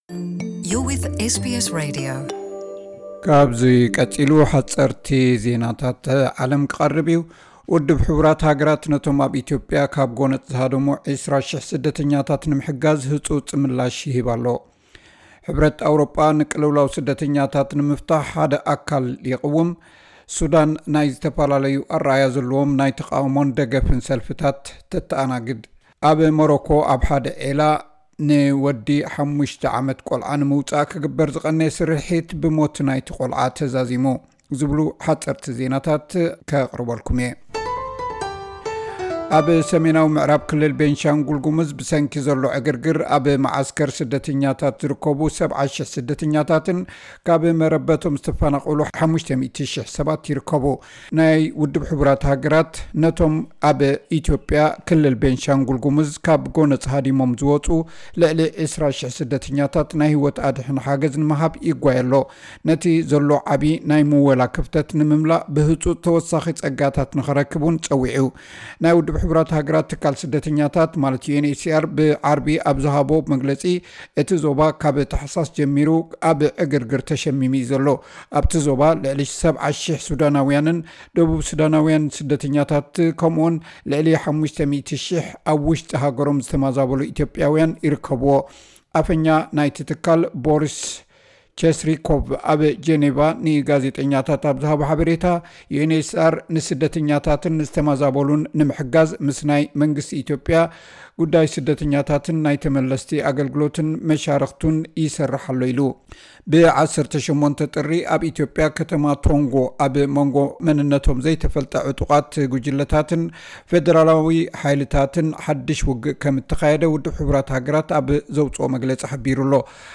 ሓጸርቲ ዜናታት